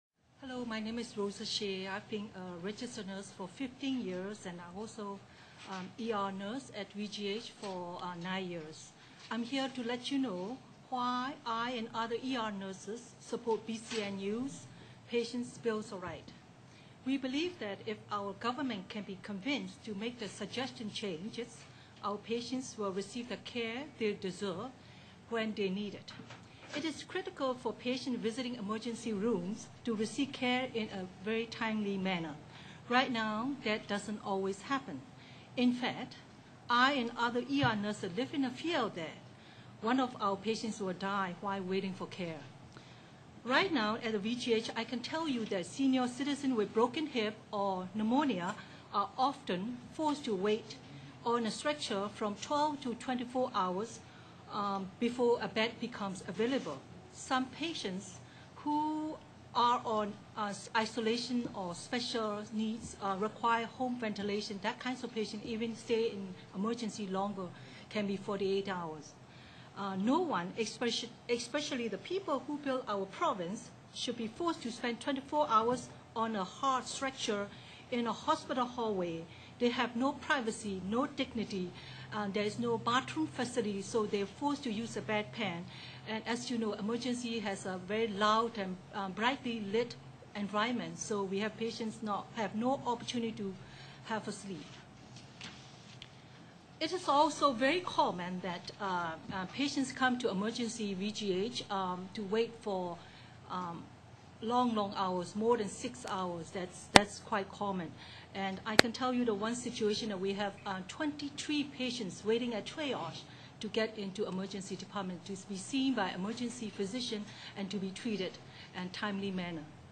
Webcast of the February 13, 2004